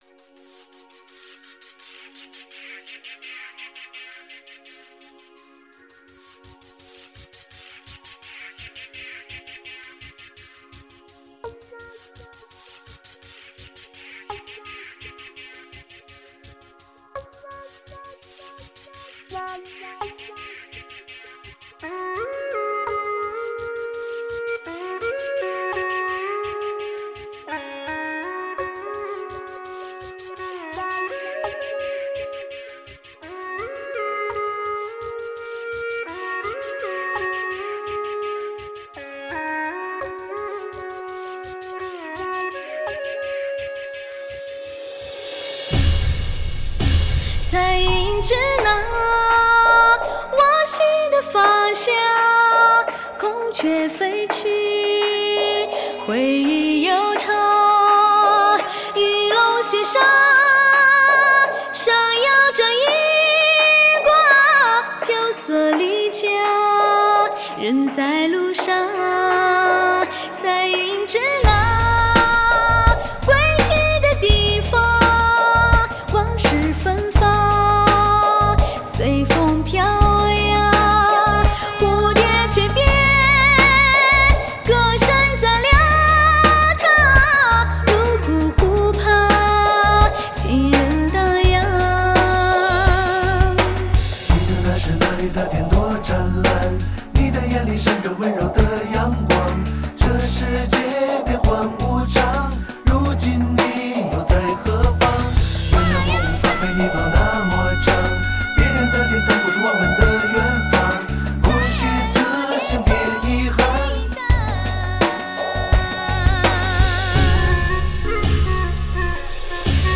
·民族色彩的再次延续 再显超自然的淳朴与真实
·原生态的自然声音 探访人间最后秘境